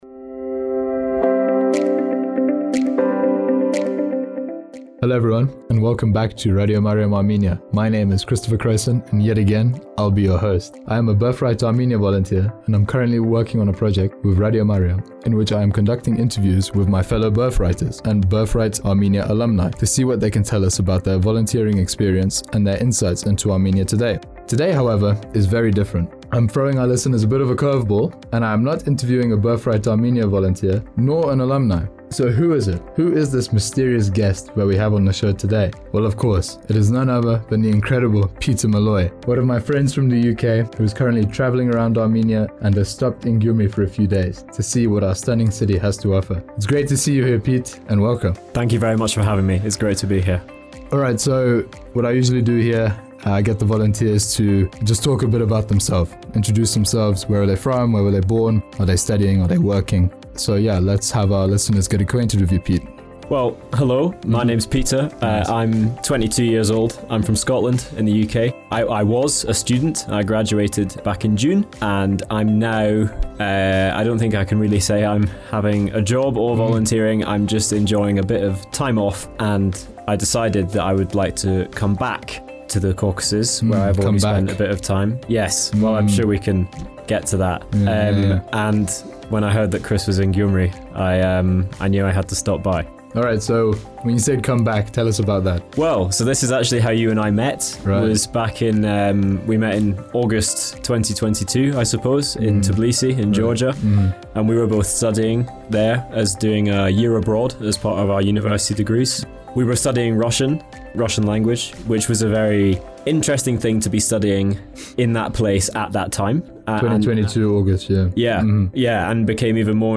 In this one-off episode, I am not speaking with a Birthright Armenia volunteer or alumnus, but with a tourist visiting our stunning city. Just like in conversations with past and present Birthright participants, listeners will get to know our special guest, learn what they are doing in Armenia and hear about their impressions of Gyumri and Armenia.